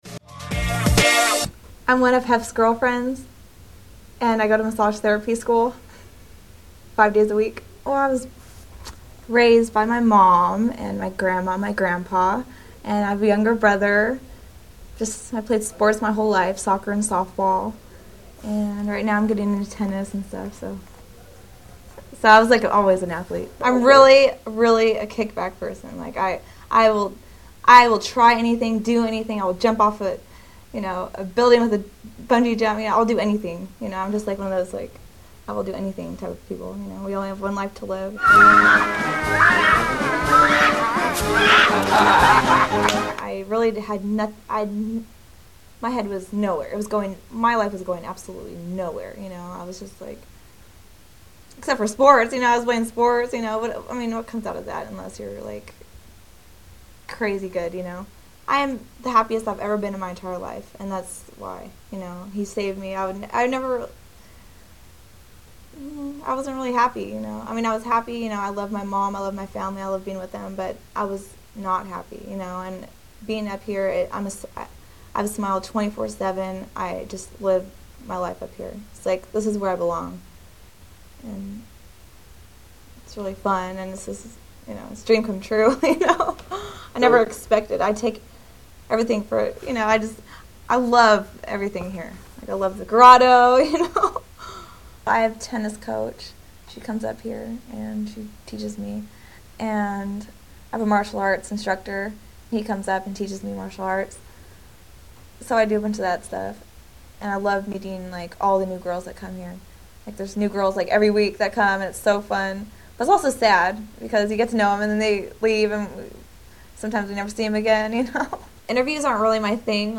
Kendra Interview